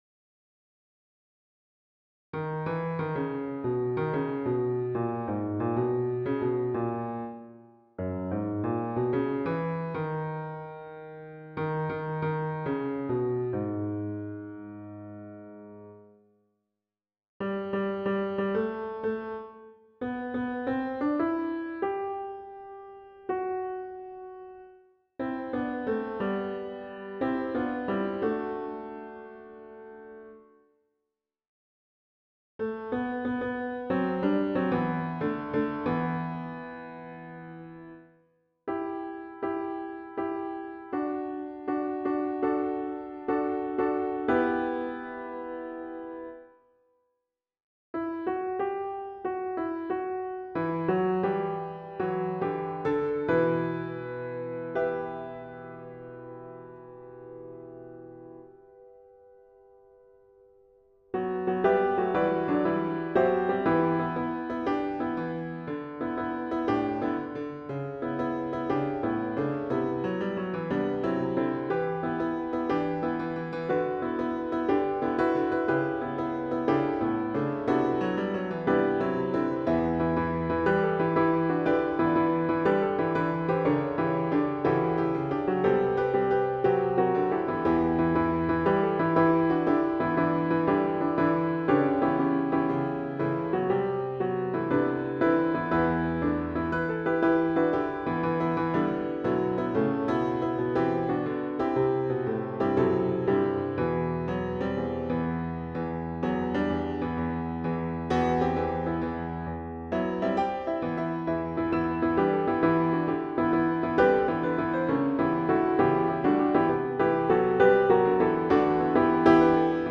Title We Are the Harmonists (SATB) Opus # 212 Year 0000 Duration 00:03:57 Self-Rating 4 Description Bring the house down.
Piano, Choral Plays